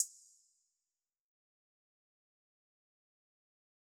Closed Hats
BZ Redd HiHat.wav